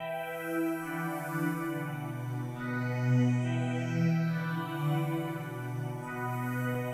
Hook Synth.wav